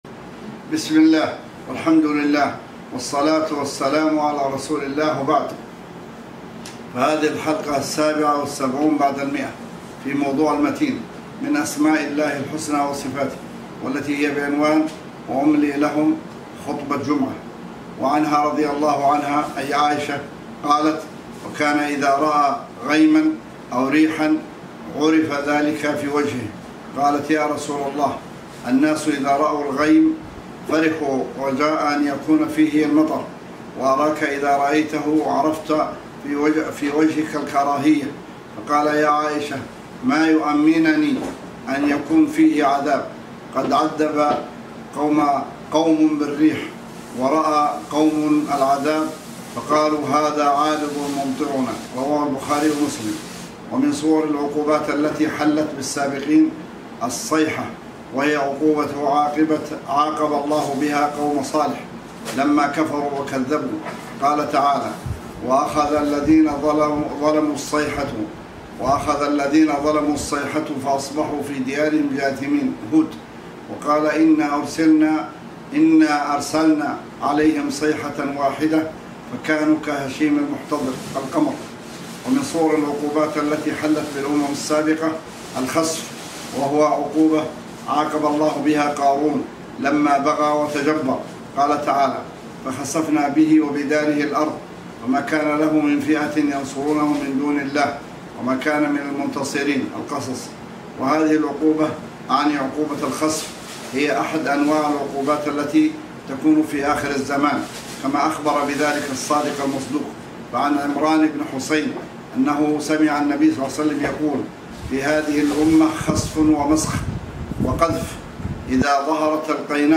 وأملي لهم – خطبة جمعة :